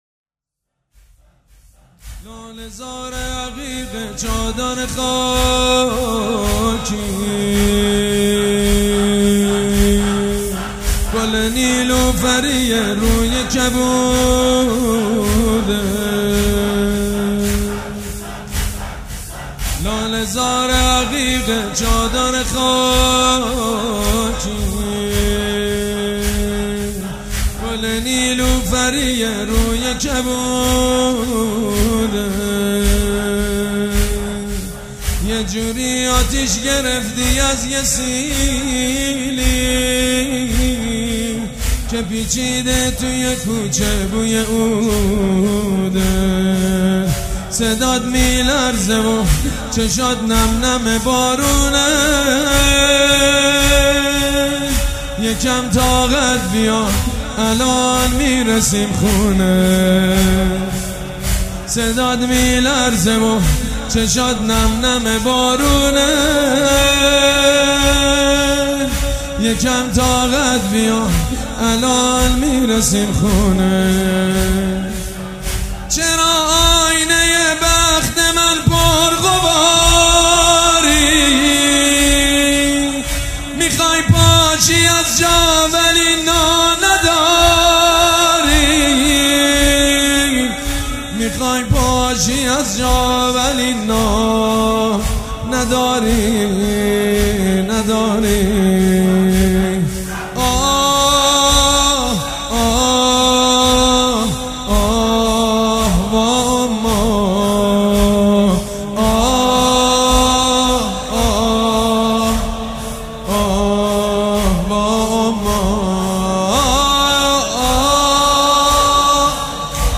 عنوان : زمینه فاطمیه